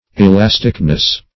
Elasticness \E*las"tic*ness\, n.